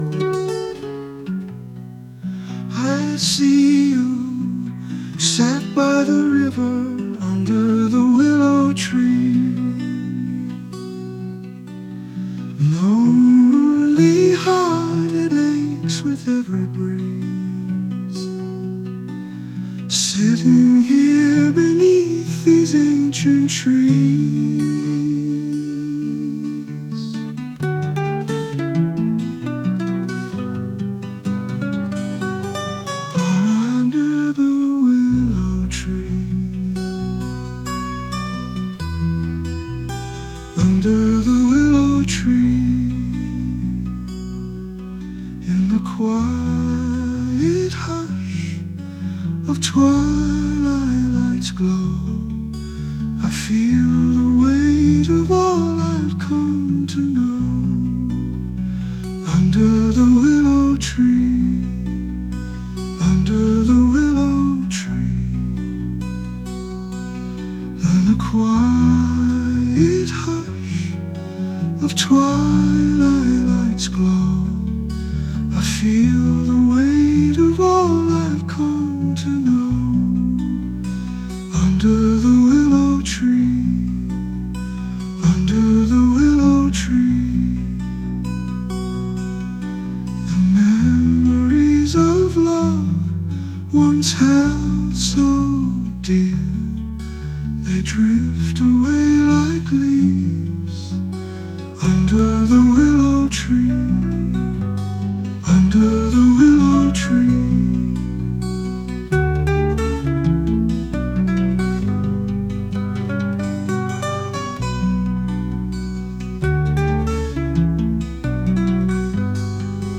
AI MUSIC / AMERICAN PRIMITIVISM
American Primitivism 2-step, sad, chill